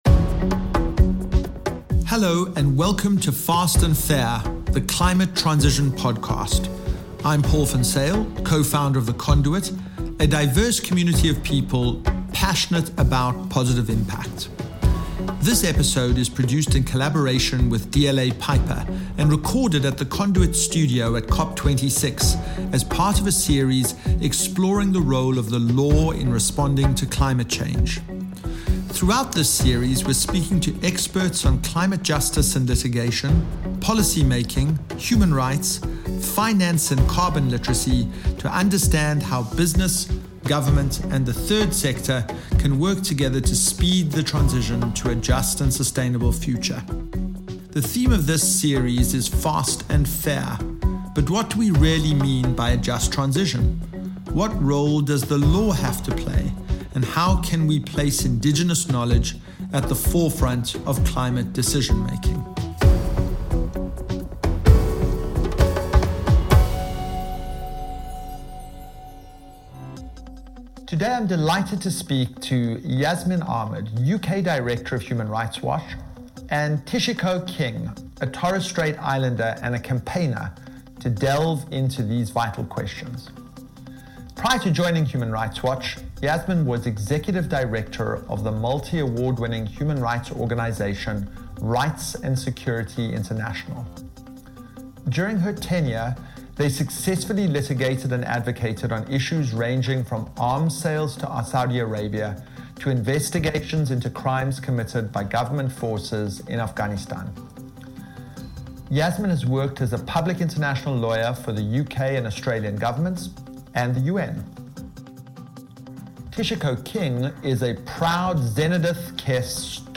They discuss what governments should be doing to protect the rights of at-risk populations, the Torres Strait islands ground-breaking court case against the Australian government, and why having a clean, healthy and sustainable environment is a human right. This episode is produced in collaboration with DLA Piper, and recorded at The Conduit Studio at COP26, as part of a series exploring the role of the law in responding to climate change.